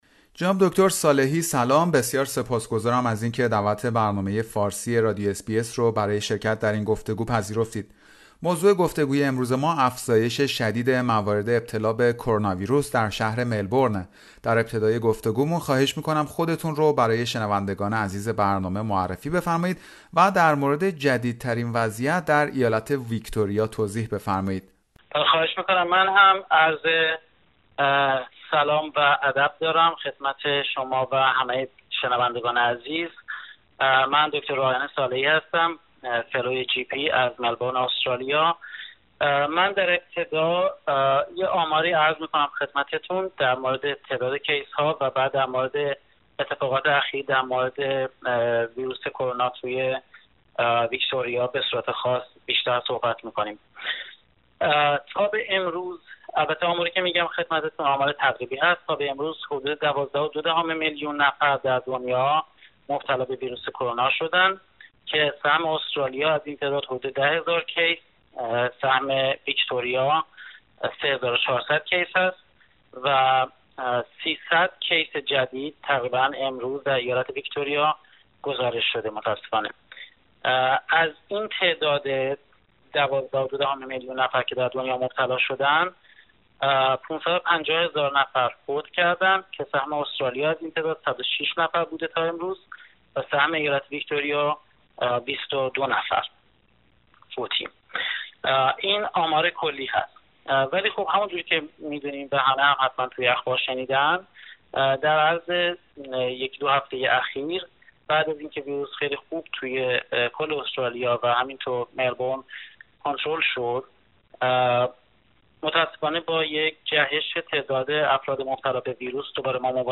گفتگو با یک پزشک در مورد نکات و توصیه های مهمی که باید در مورد وضعیت کروناویروس در ملبورن بدانید